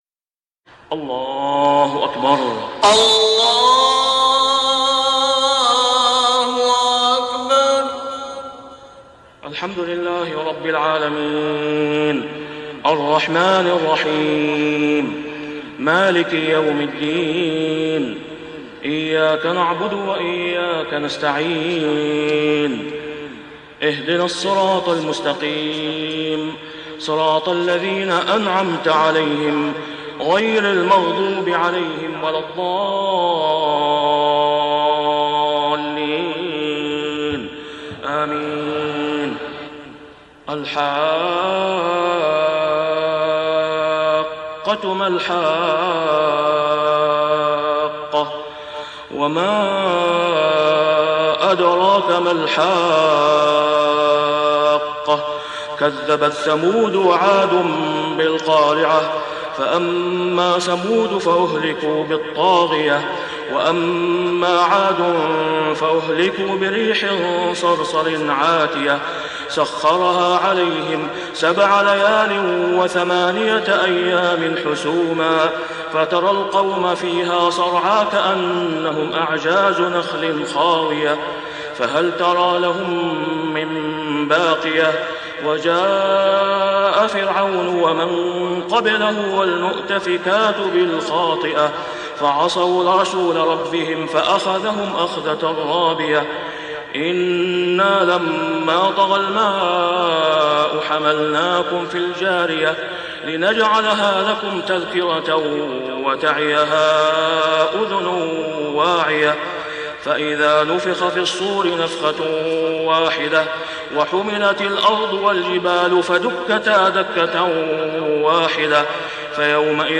( صلاة الفجر و العام غير معروف ) | سورة الحاقة كاملة > 1424 🕋 > الفروض - تلاوات الحرمين